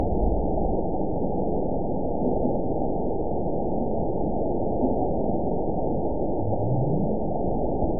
event 920352 date 03/18/24 time 03:18:12 GMT (1 year, 1 month ago) score 9.61 location TSS-AB02 detected by nrw target species NRW annotations +NRW Spectrogram: Frequency (kHz) vs. Time (s) audio not available .wav